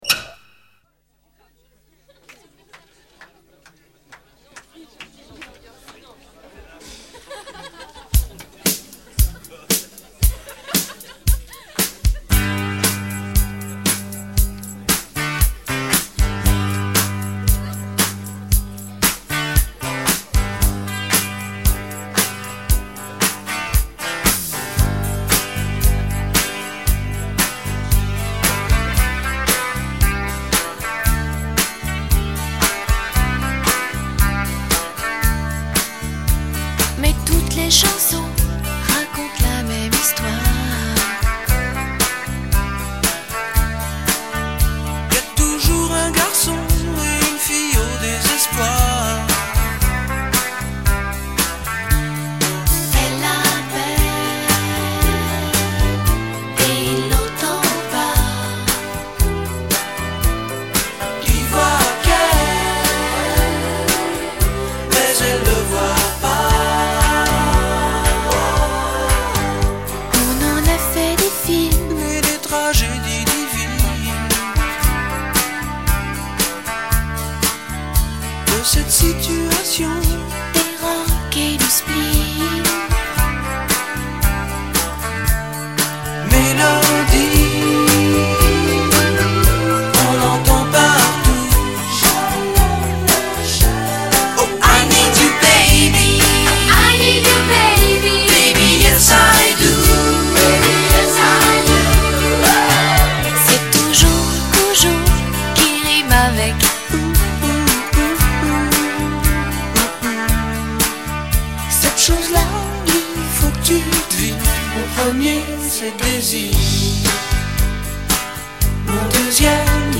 Tonalité DO majeur